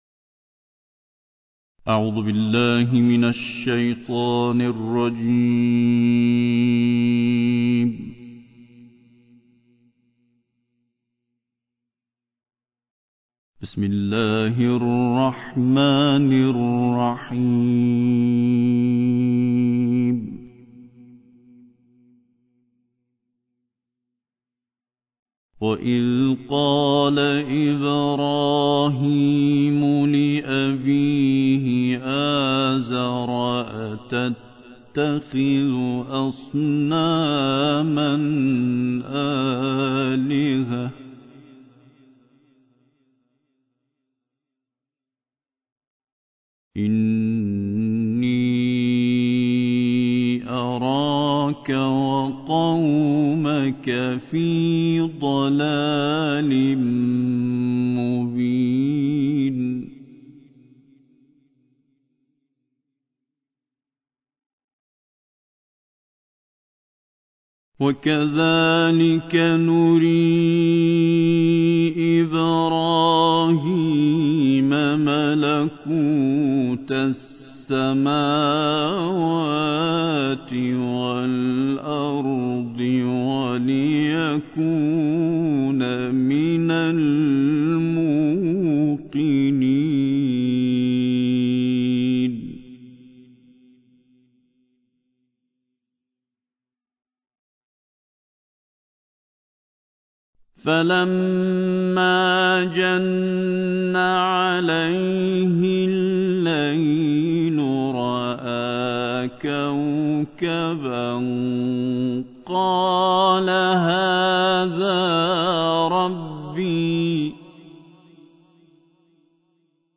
دانلود قرائت سوره انعام آیات 74 تا 91 - استاد سعید طوسی